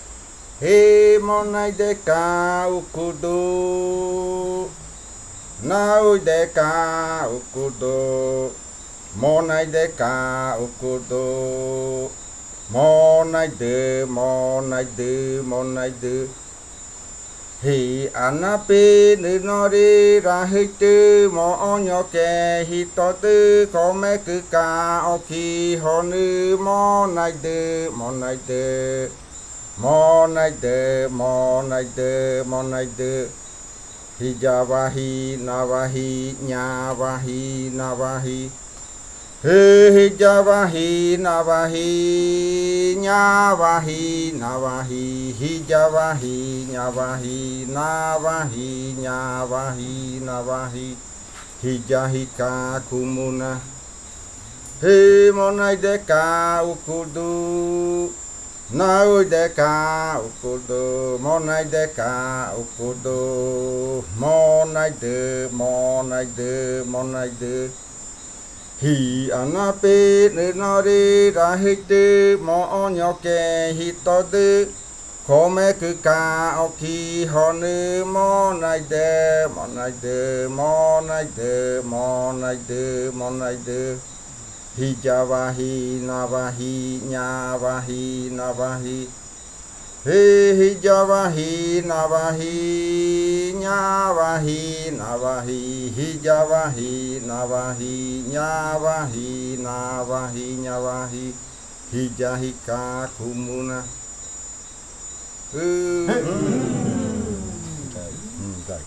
Leticia, Amazonas, (Colombia)
Grupo de danza Kaɨ Komuiya Uai
Canto de fakariya de la variante jaiokɨ (canntos de culebra).
Fakariya chant of the Jaiokɨ variant (Snake chants).